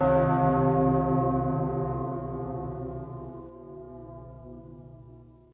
Bell3.ogg